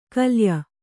♪ kalya